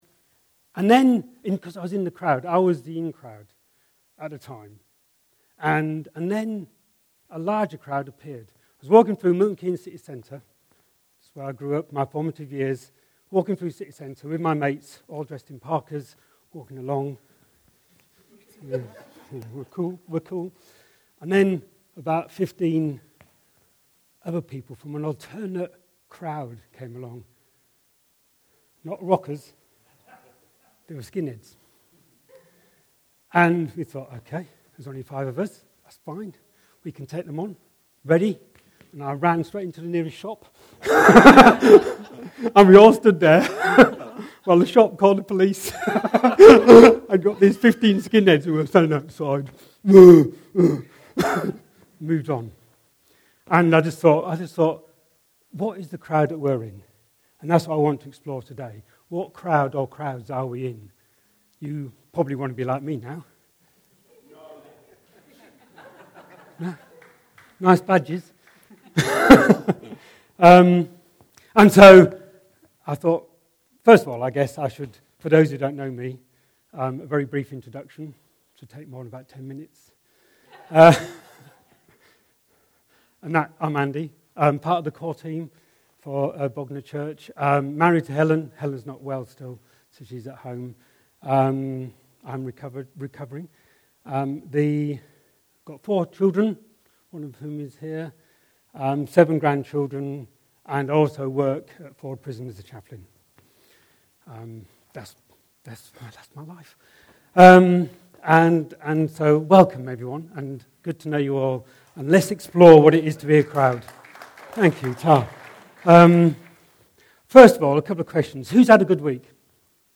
Other Sermons 2025